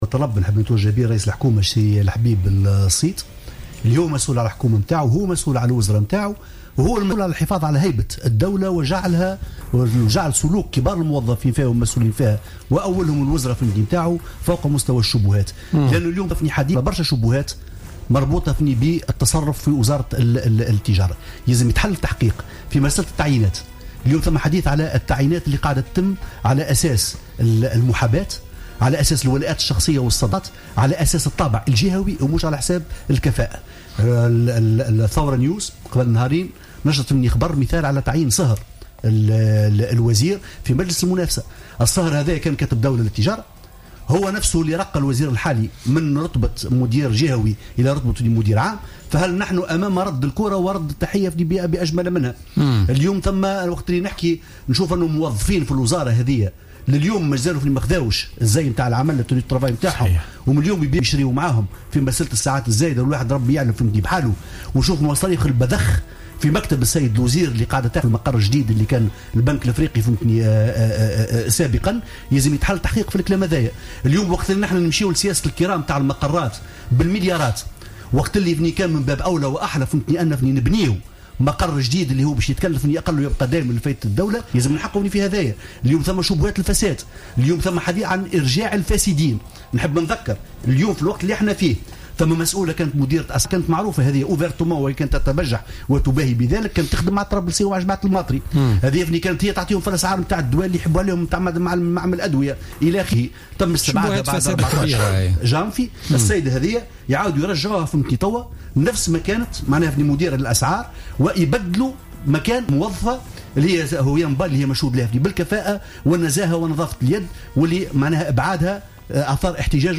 وقال في برنامج "بوليتيكا" بـ"الجوهرة أف أم" إنه يتوجه بطلبه لرئيس الحكومة للتحقيق في التعيينات بوزارة التجارة التي تتم على أساس الولاءات وصلة القرابة والمحاباة والجهويات. وأكد أن هناك شبهات فساد بهذه الوزارة كما تم السماح لمتهمين بالفساد للعودة للعمل بالوزارة، بالإضافة إلى قرارت مريبة للتمديد في تقاعد بعض الموظفين فيما يتم إقصاء موظفين شرفاء بالوزارة.